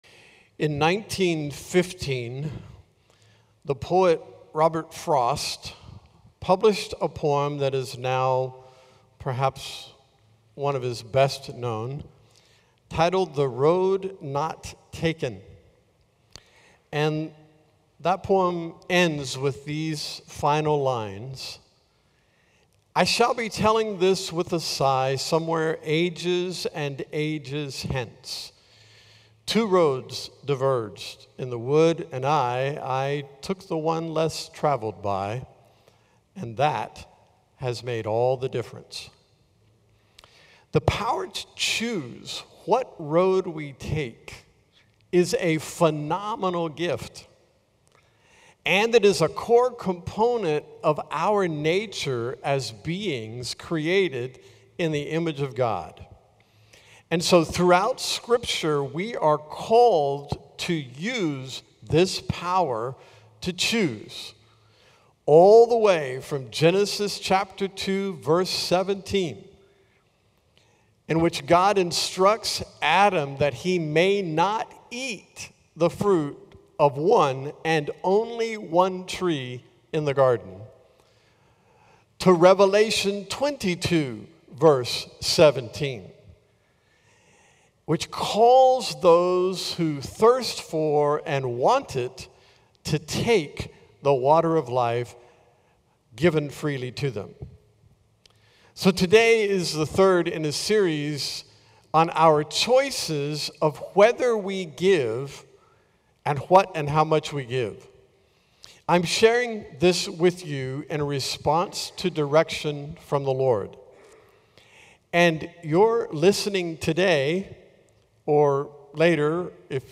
A message from the series "When We Give."